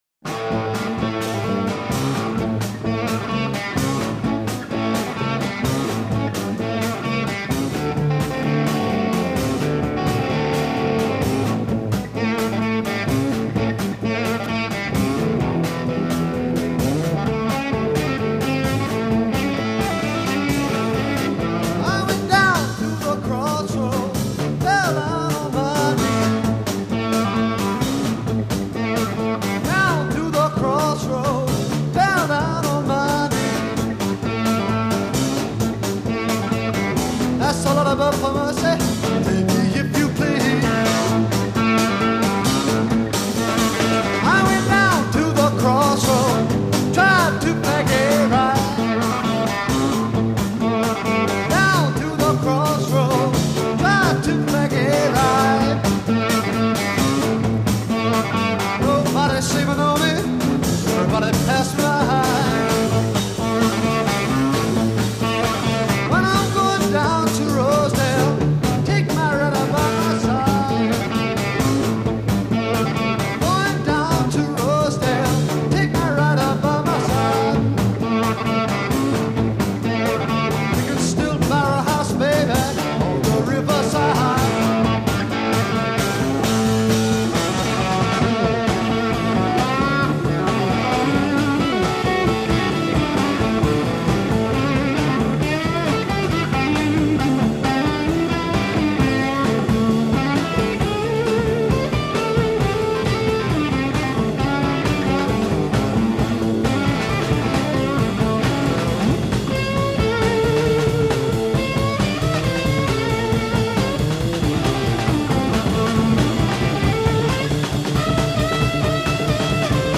bass
guitar & vocal
drums
Introduction 12 Guitar solo over drums and bass.
Verse 12 Drums and bass back to regular pattern; guitar off.
Transatlantic Psychedelic Blues